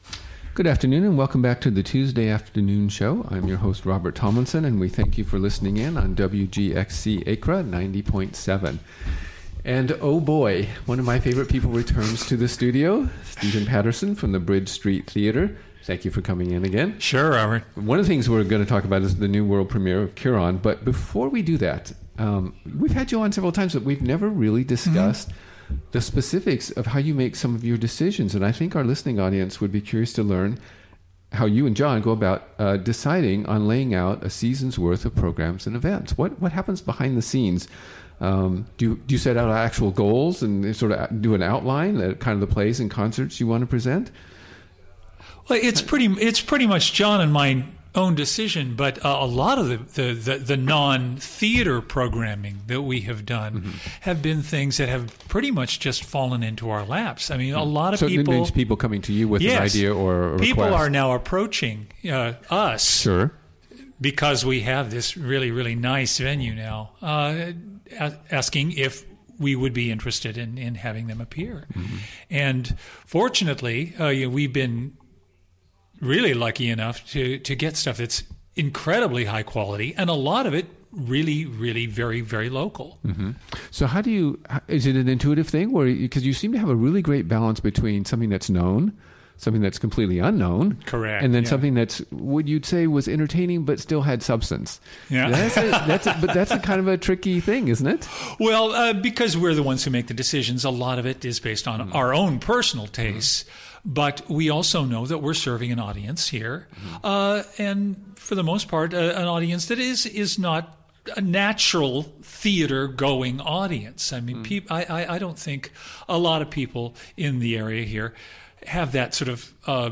Recorded on the WGXC Afternoon Show on April 18, 2017.